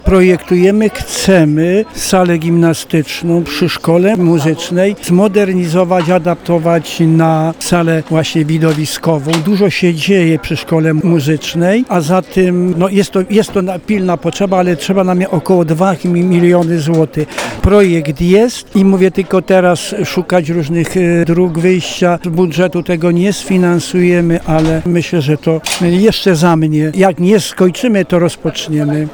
Mówi burmistrz Radomyśla Wielkiego, Józef Rybiński.